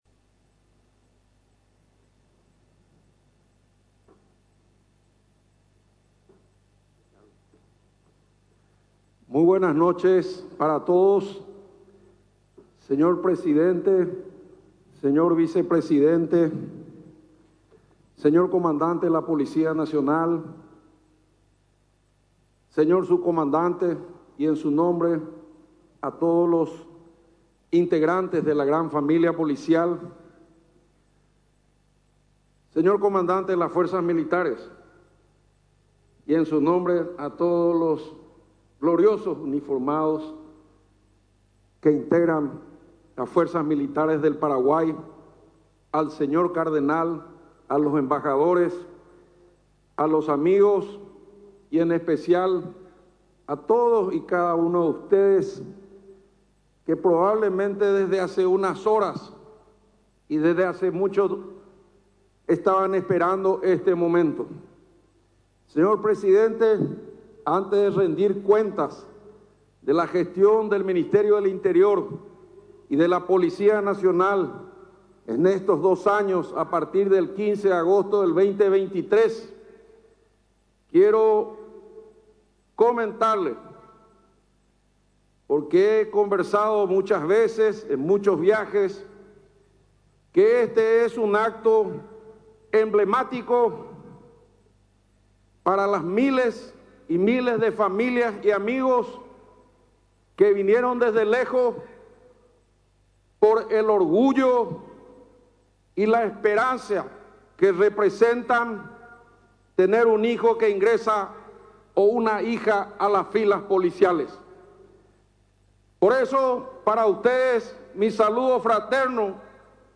El acto incluyó la entrega simbólica de títulos, juramento de los nuevos suboficiales y mensajes de las principales autoridades nacionales.